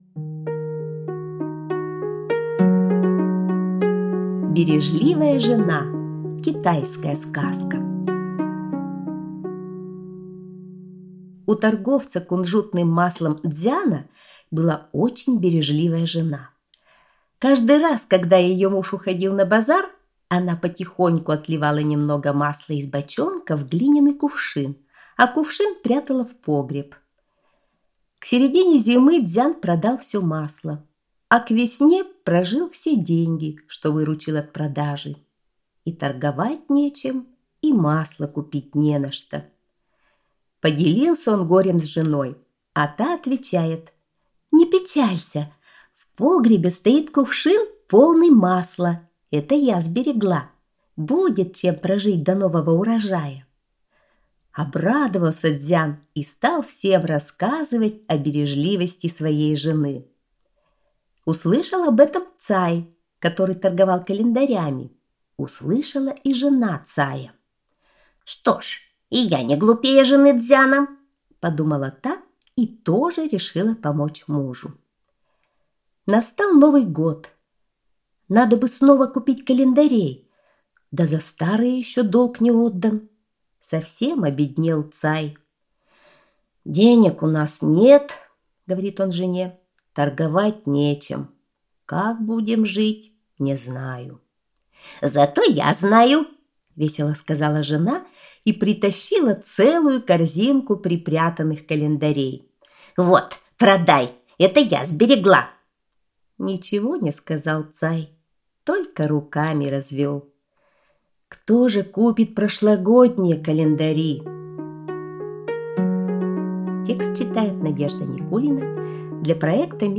Бережливая жена – китайская аудиосказка